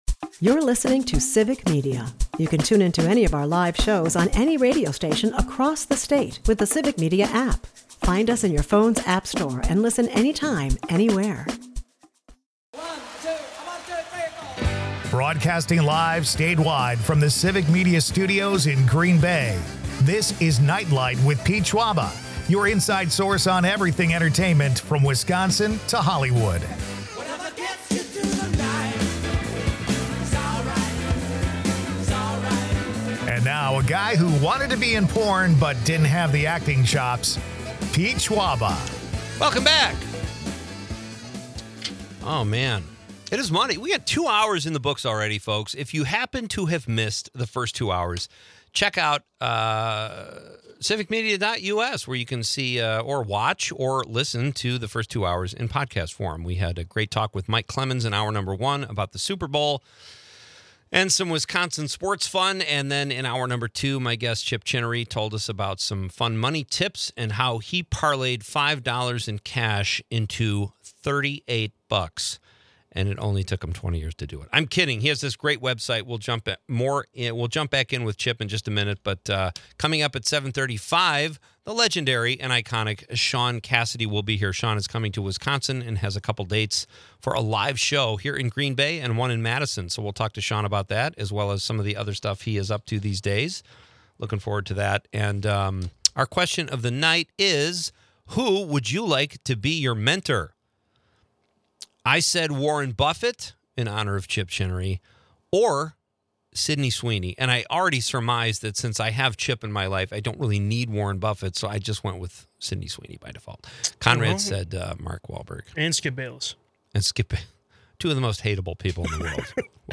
Cassidy reflects on his transition from teen idol to TV writer for hits like 'American Gothic.' The episode is peppered with audience interactions, quirky mentor choices, and surprising celebrity tidbits that keep things lively.